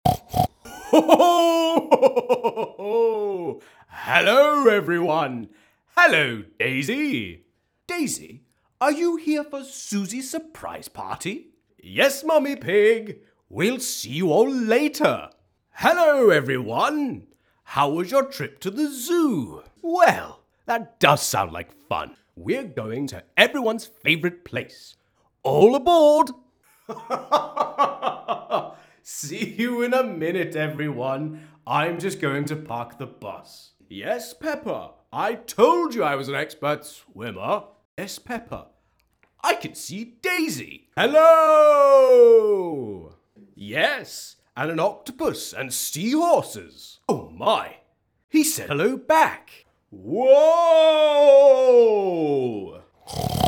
VOICE REEL
Scottish Actor.